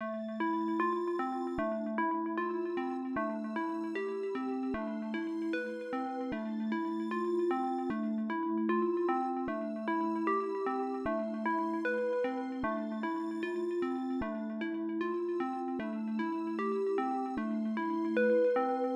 Murda 152 bpm_Master.wav